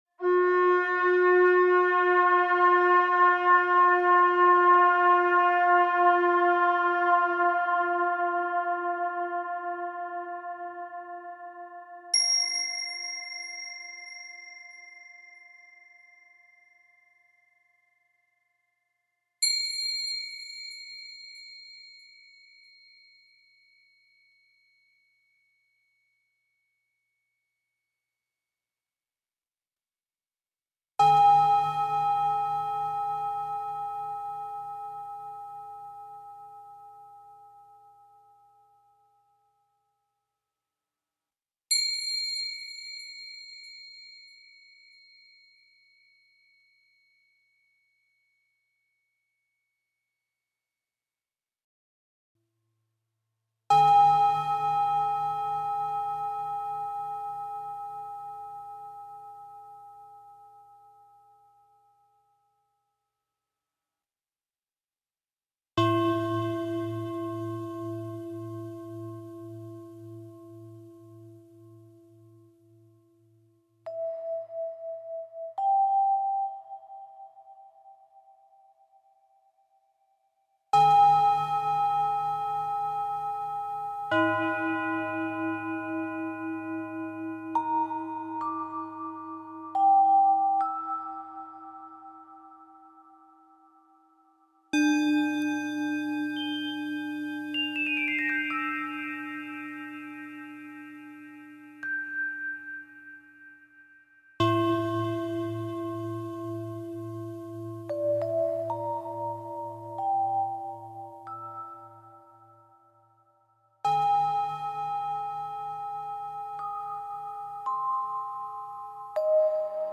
por motivo de la inaguración del Parque